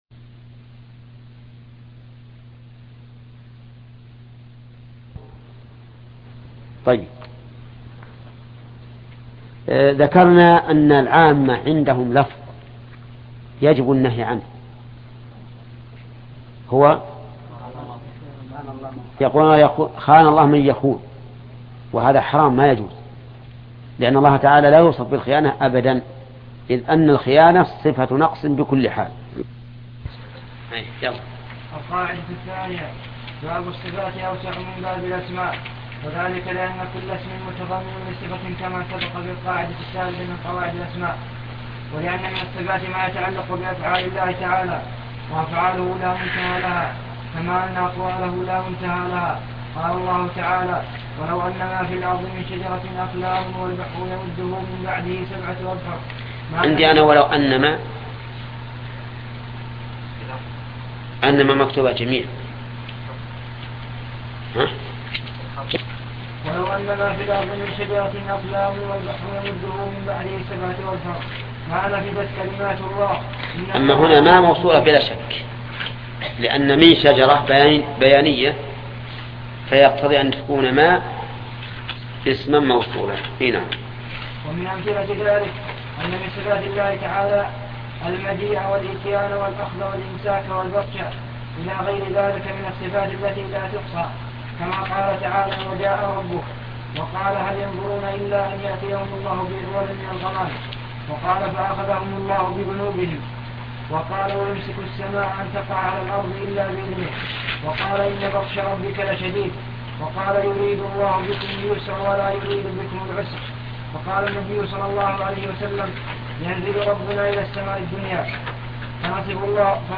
القواعد المثلى في صفات الله وأسمائه الحسنى شرح الشيخ محمد بن صالح العثيمين الدرس 4